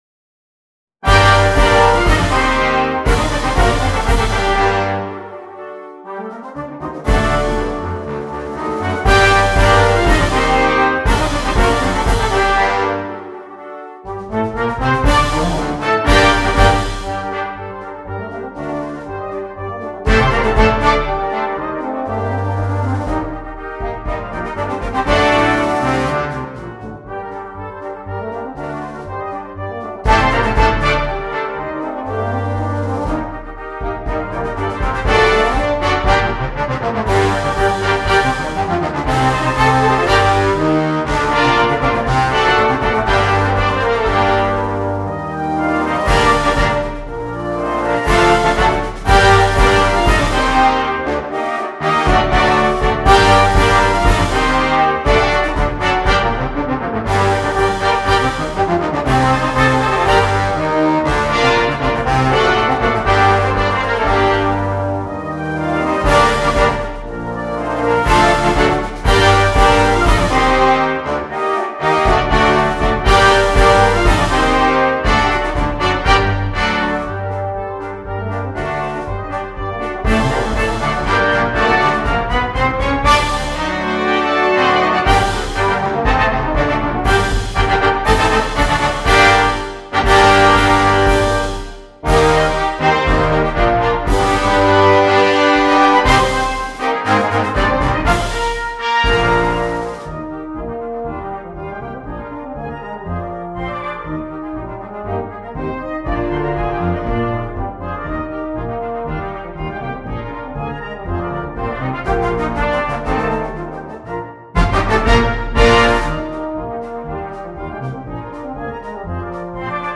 2. Brass Band
Full Band
without solo instrument
March
Quick March